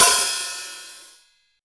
PERC.6.NEPT.wav